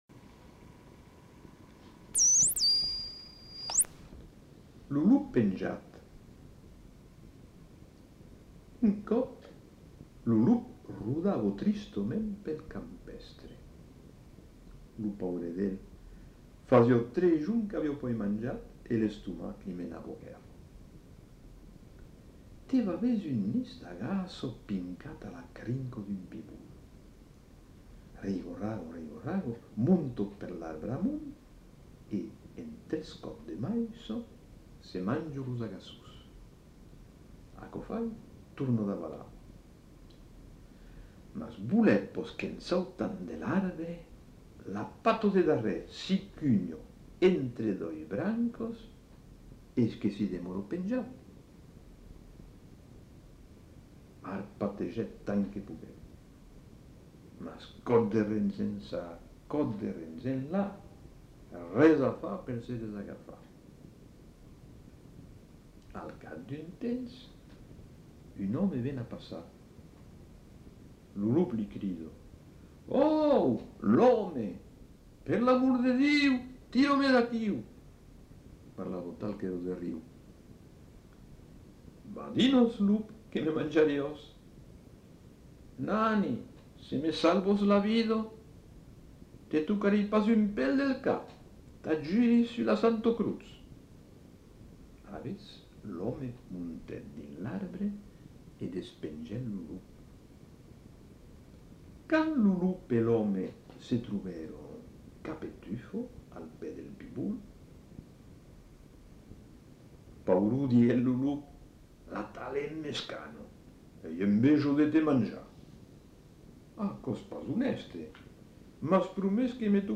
Genre : conte-légende-récit
Effectif : 1
Type de voix : voix d'homme
Production du son : lu